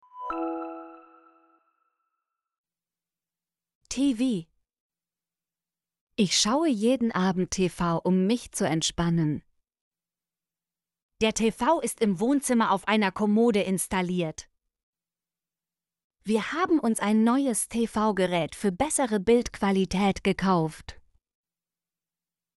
tv - Example Sentences & Pronunciation, German Frequency List